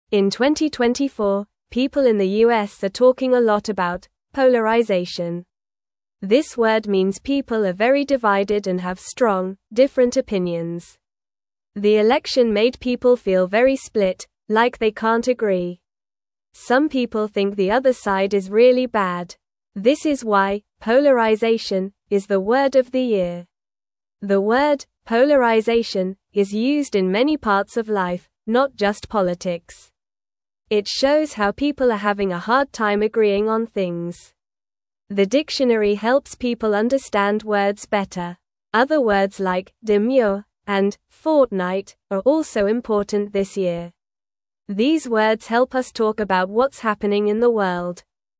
Normal
English-Newsroom-Beginner-NORMAL-Reading-People-Are-Divided-The-Word-of-the-Year.mp3